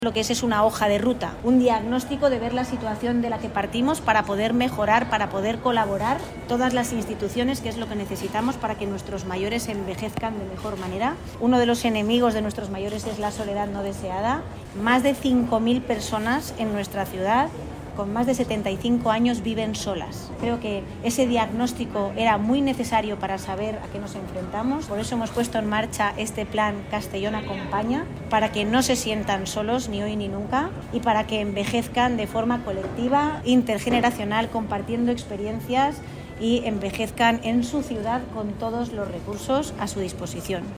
Corte de voz alcaldesa de Castellón, Begoña Carrasco